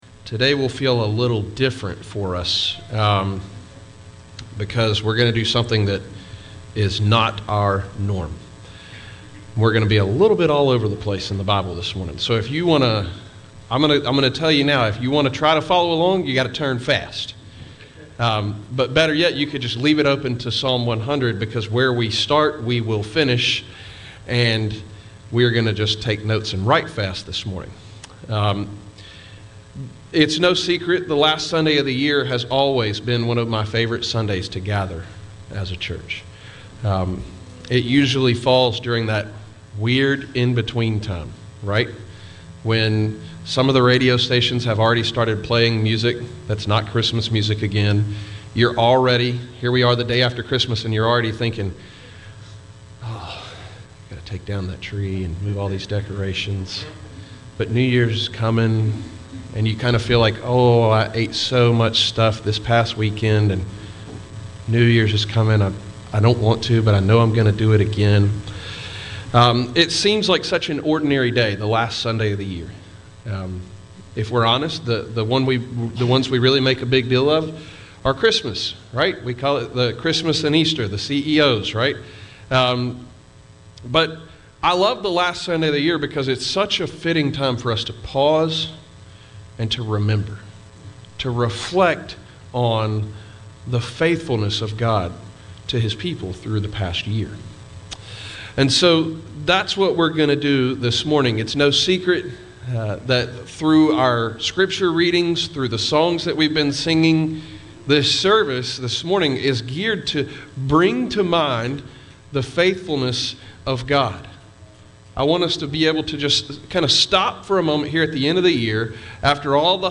Visit Who We Are What We Believe Leadership Connect Children Students Adults Events Sermons Give Contact Our Faithful God December 26, 2021